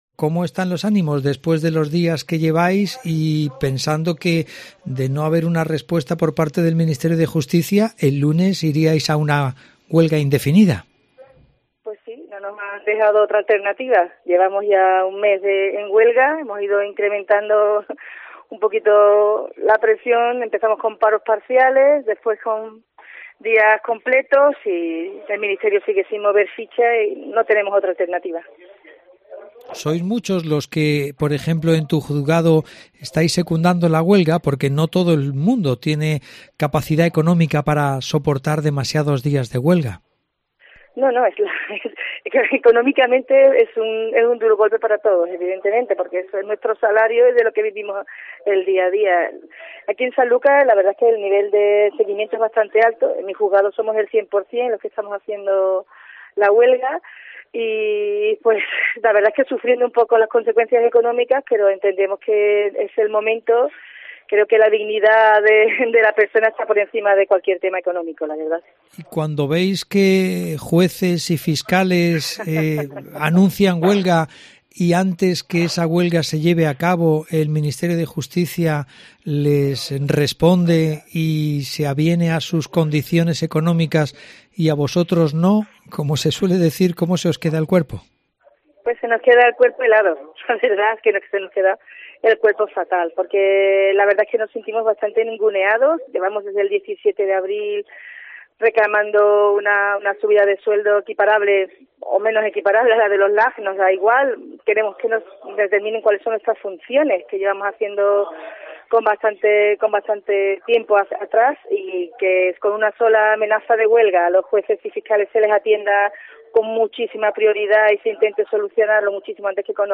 Entrevistamos a una funcionaria en huelga del Juzgado Nº 4 de Sanlúcar de Barrameda (Cádiz)